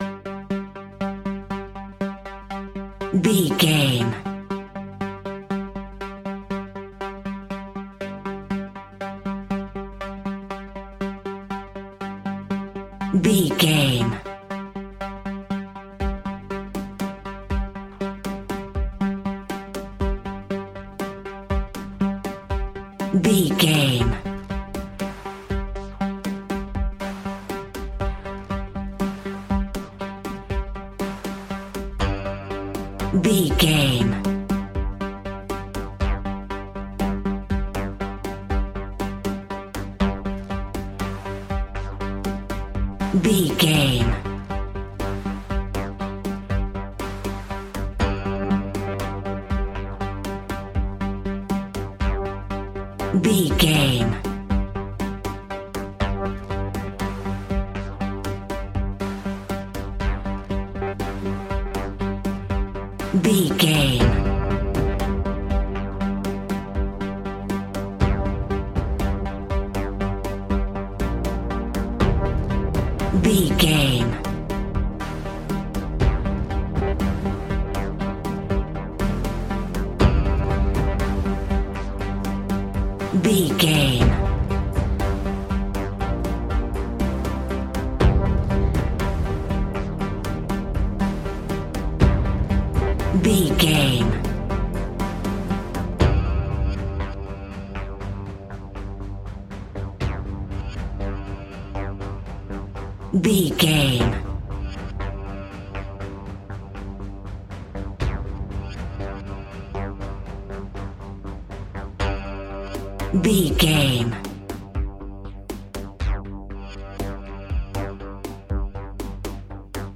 Aeolian/Minor
ominous
haunting
eerie
synthesiser
drum machine
mysterious
horror music